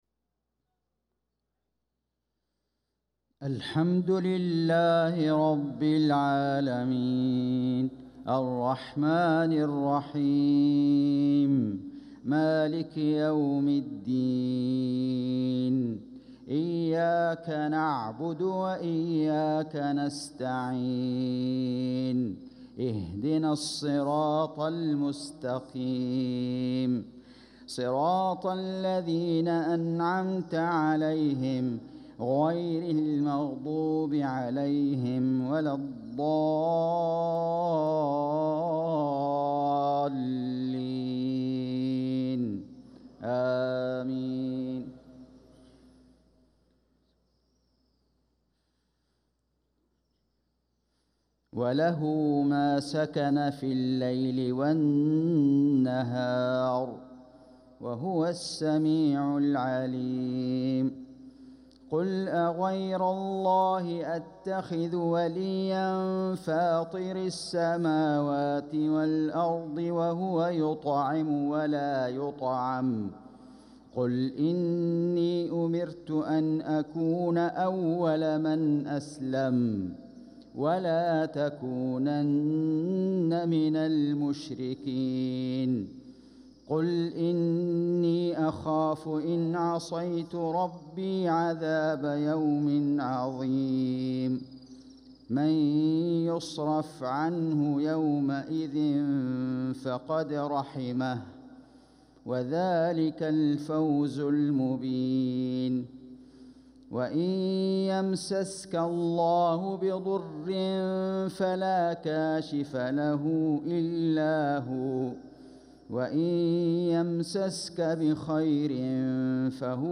صلاة المغرب للقارئ فيصل غزاوي 26 صفر 1446 هـ
تِلَاوَات الْحَرَمَيْن .